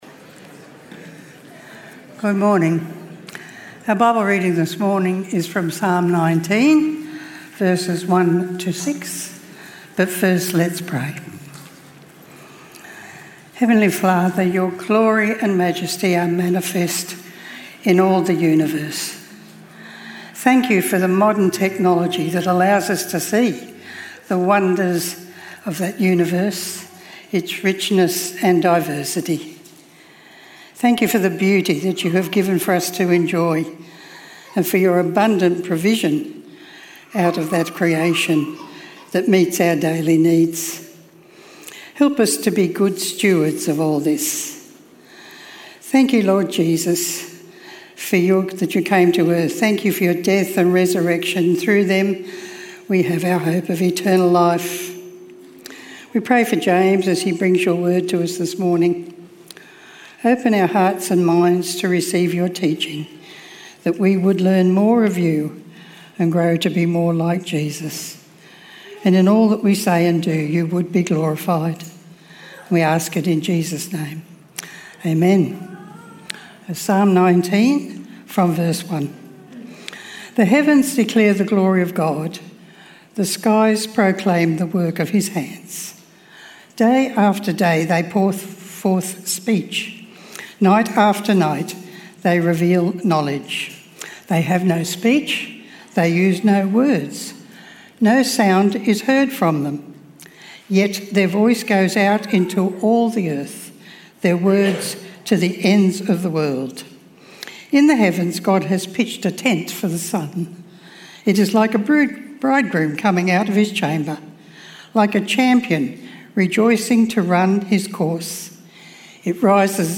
CreationTalk.mp3